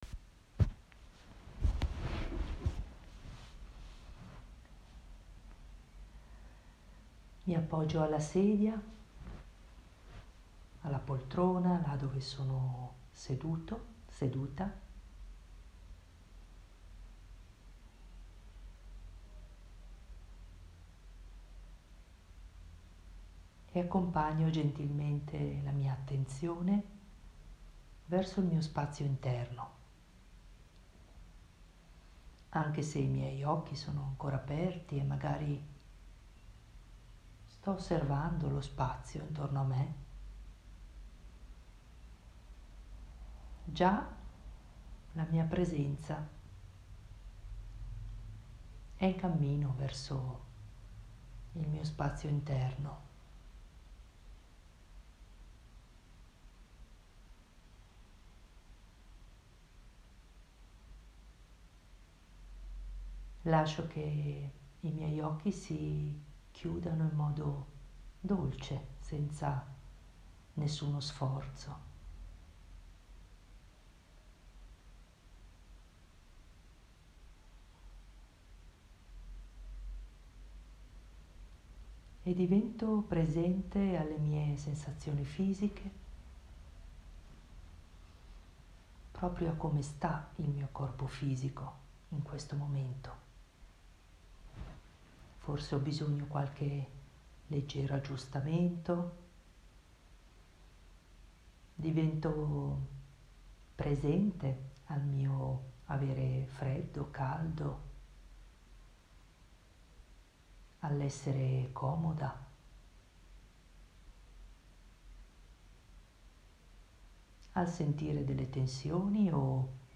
meditazione5.m4a